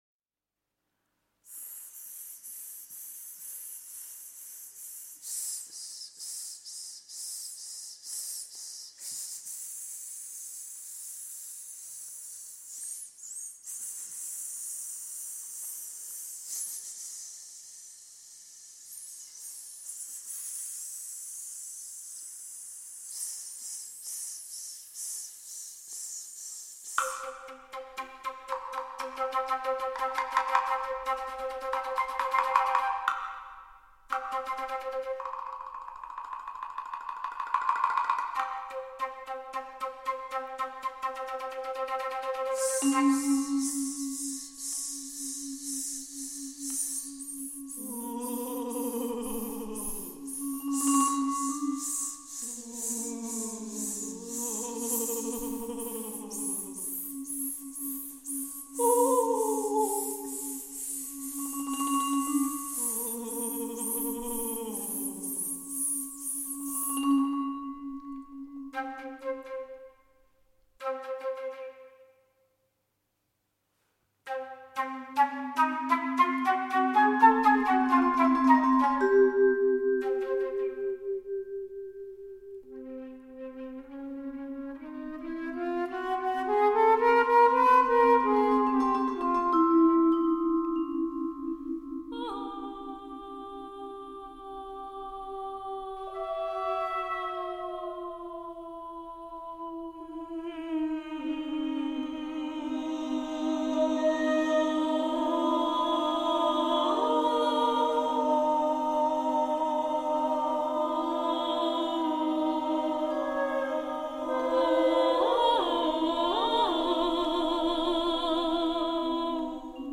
Pirmatskaņojums 29.04.2000. Anglikāņu baznīcā Rīgā
Žanrs: Vokāli instrumentālā mūzika